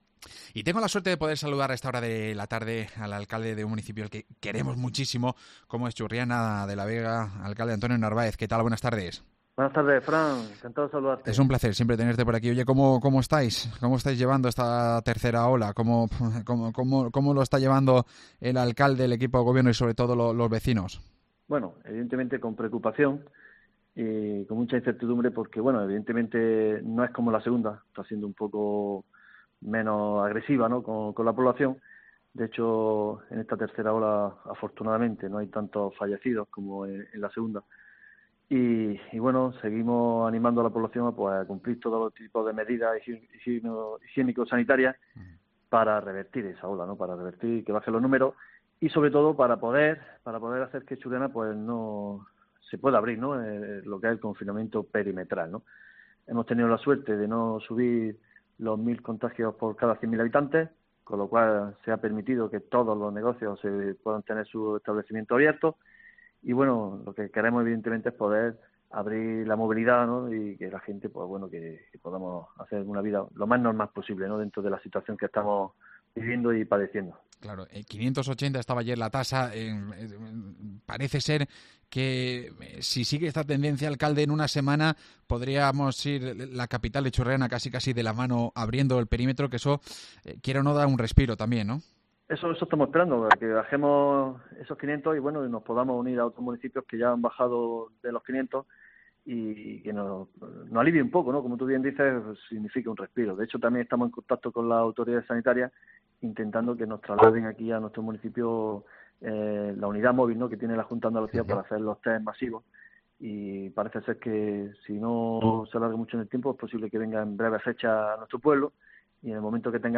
Hablamos con su alcalde, Antonio Narváez